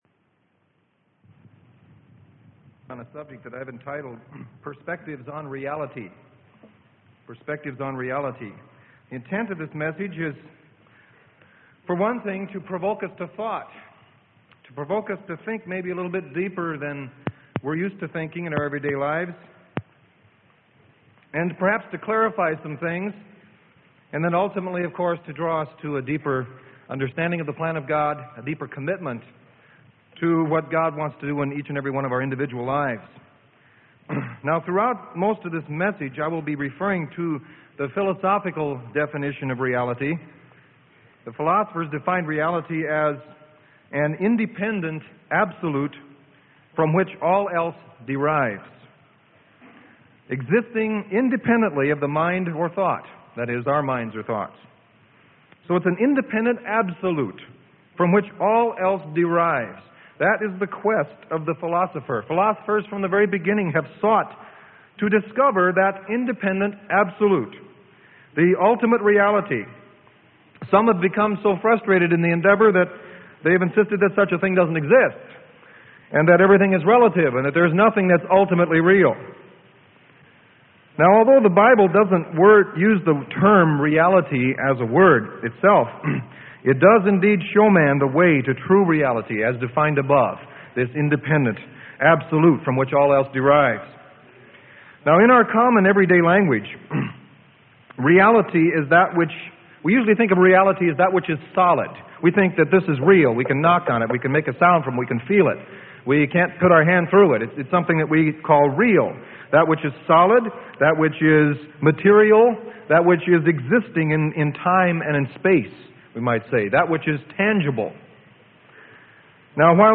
Sermon: Perspectives On Reality - Freely Given Online Library